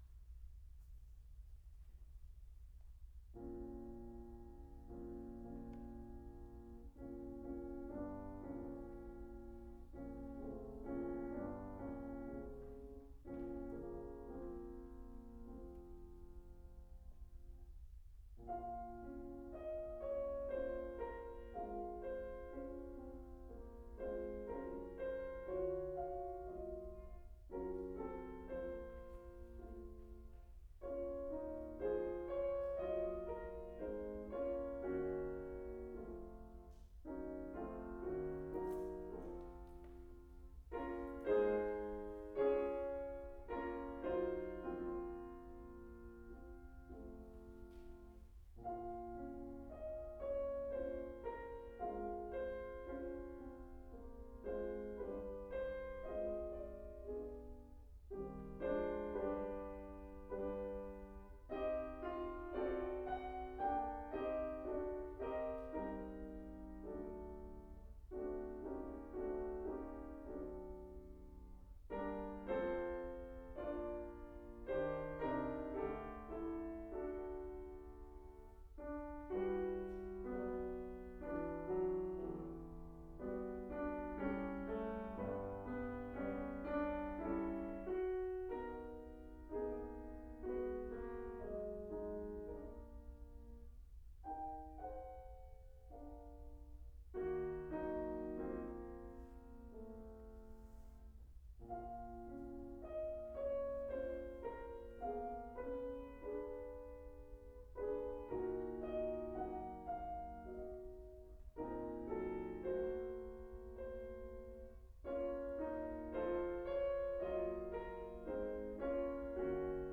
隠れた名曲コンサート　ピアノを演奏しました